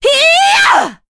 Epis-Vox_Casting4.wav